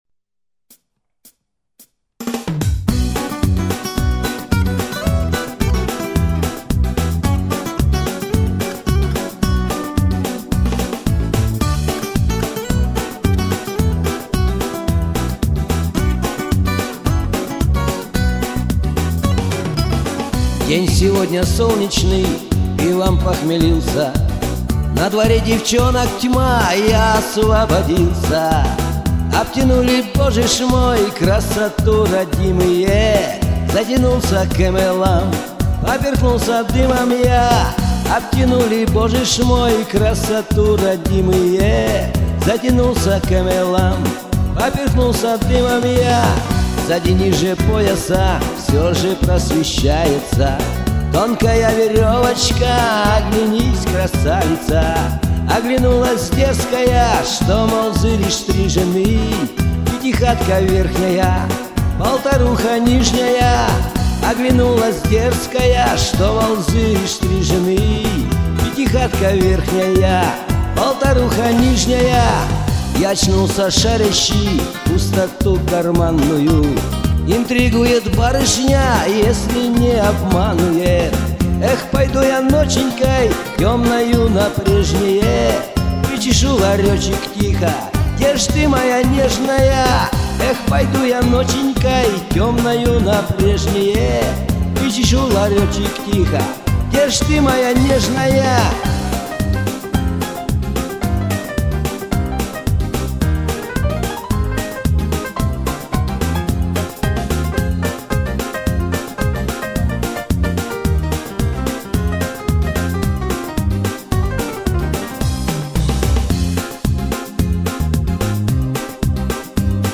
ДАВАЙТЕ ОТОРВЕМСЯ ПОД НАСТОЯЩИЙ ШАНСОНЧИК!